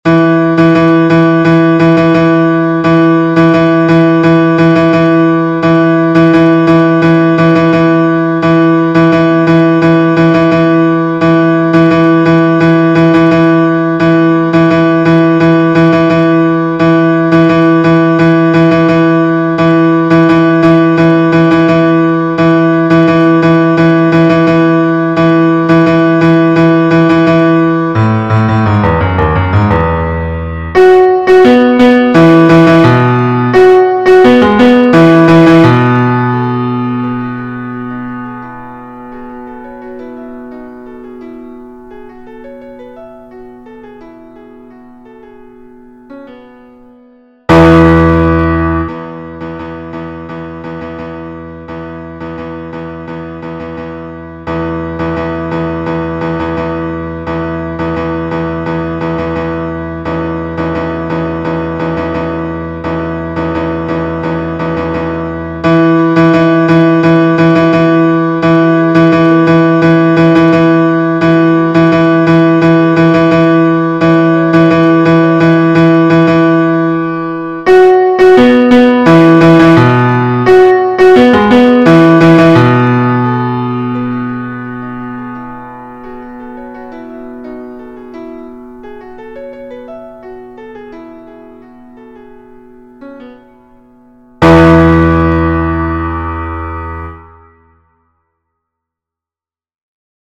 - Chant pour 4 voix mixtes SATB
MP3 versions piano
Basse Version Piano